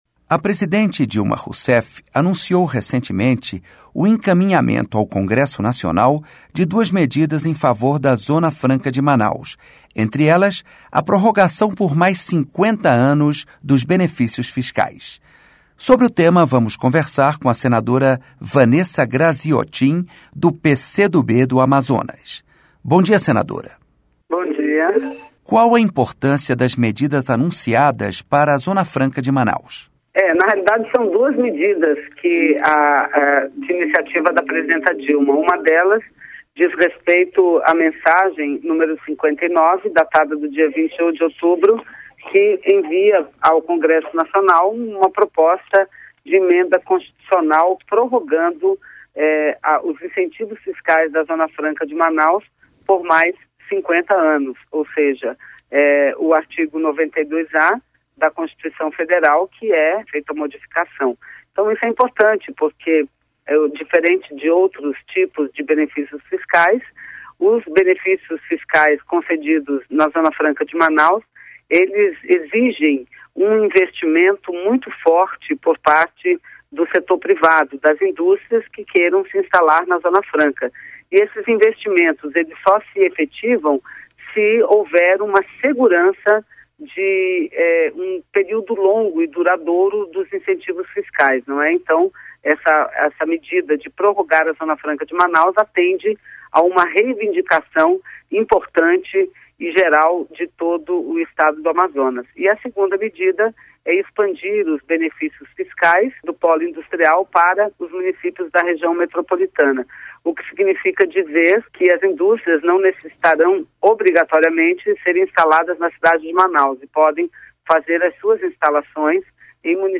Entrevista com a senadora Vanessa Grazziotin (PC do B - AM).